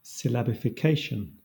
Ääntäminen
Southern England
IPA : /sɪˌlæbɪfɪˈkeɪʃən/